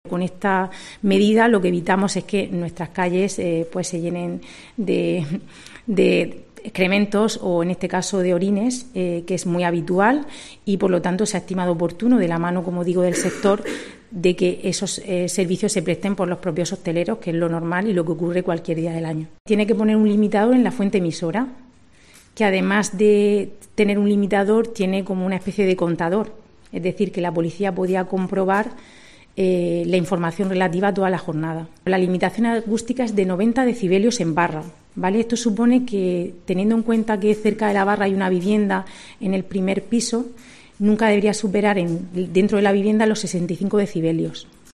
Rebeca Pérez, vicealcaldesa de Murcia